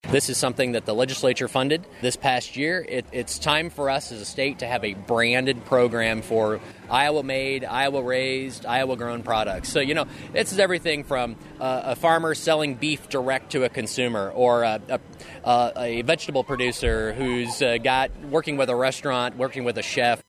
IOWA SECRETARY OF AGRICULTURE MIKE NAIG IS AT THE IOWA STATE FAIR, TOUTING THE NEW “CHOOSE IOWA” PROGRAM: